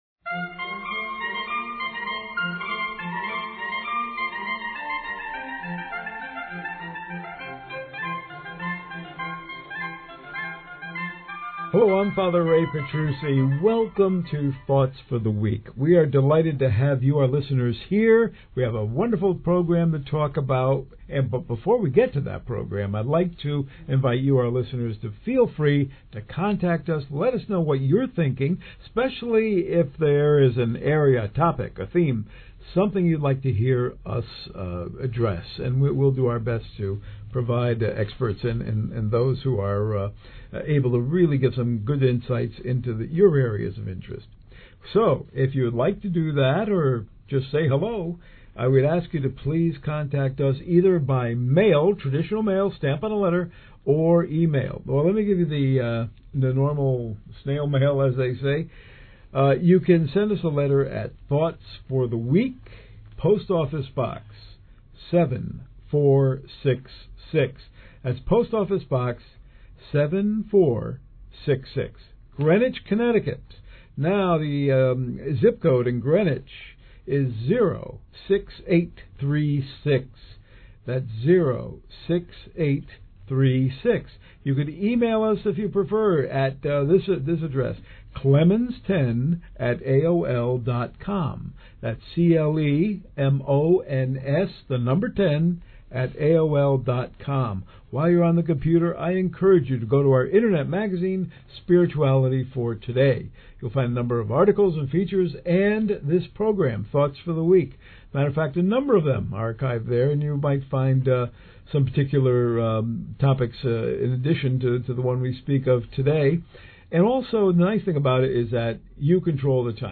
Thoughts for the Week Weekly Radio Program January 29 Featuring